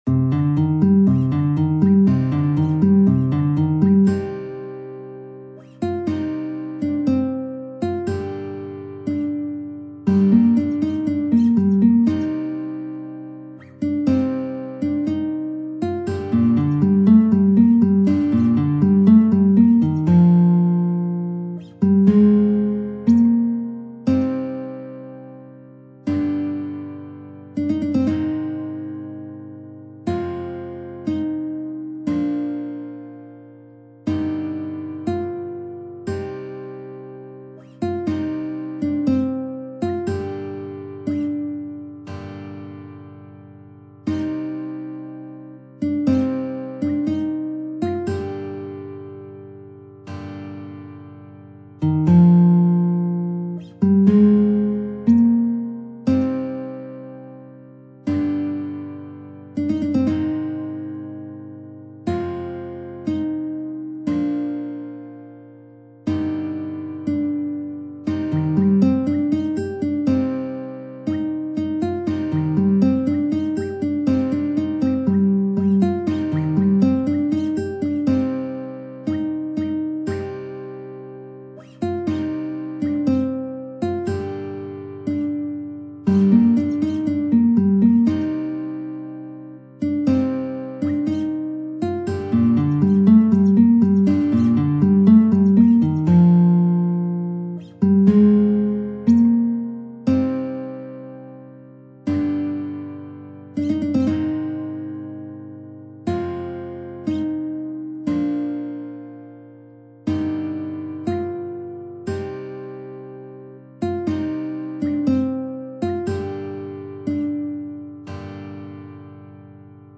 نت ملودی به همراه تبلچر و آکورد